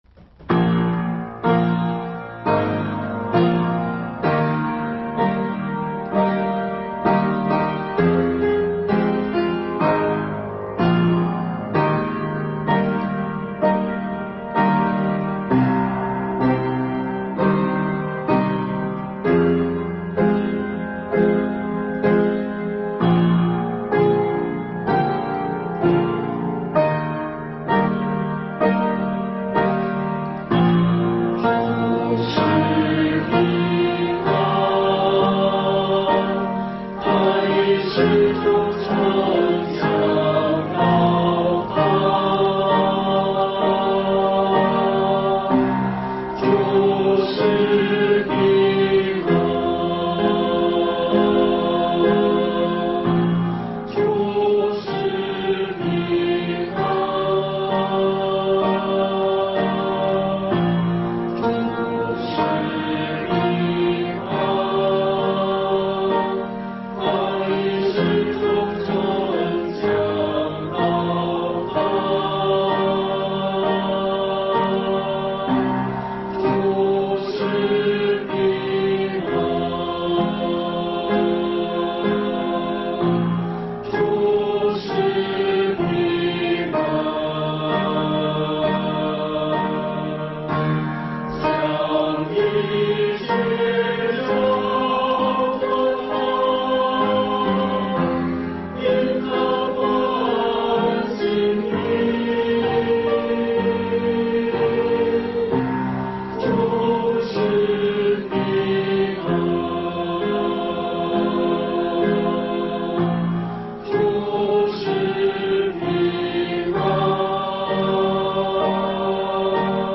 证道内容： 路加福音16章19-31节记载了财主和讨饭的拉撒路在地上的生活以及亚伯拉罕与财主在永恒当中的对话。